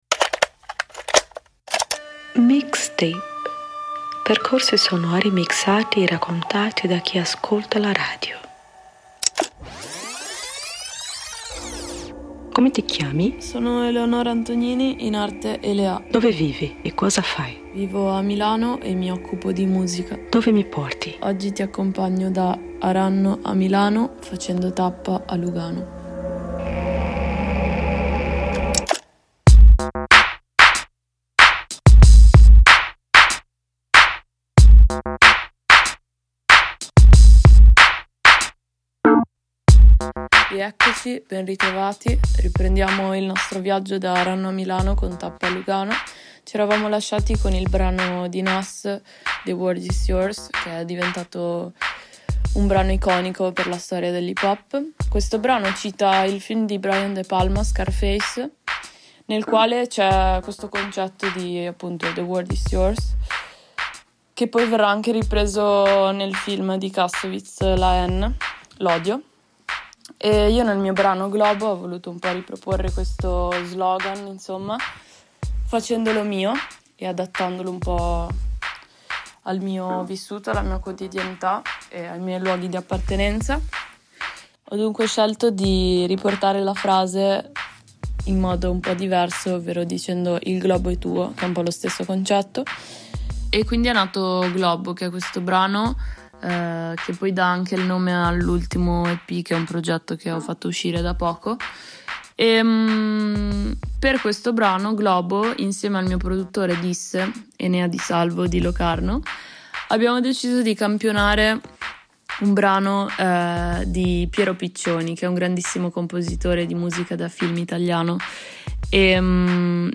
Percorsi sonori mixati e raccontati da chi ascolta la radio